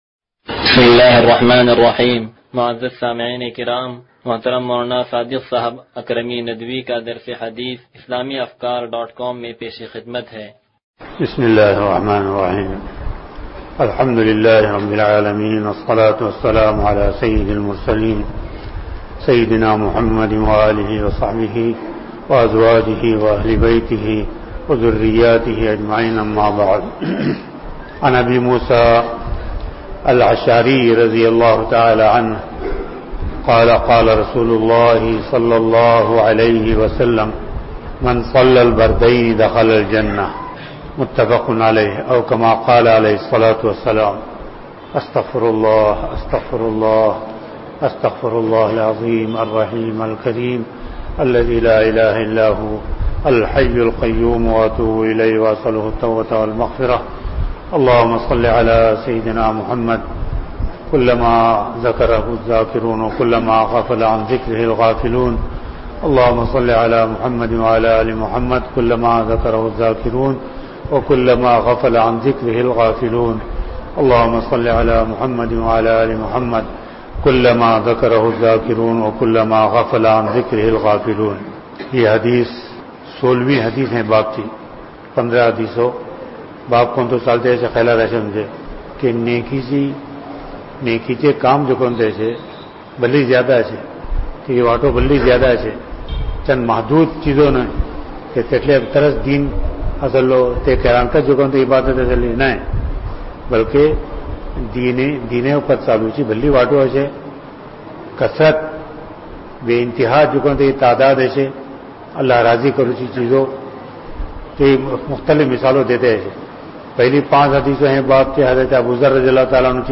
درس حدیث نمبر 0139
درس-حدیث-نمبر-0139-2.mp3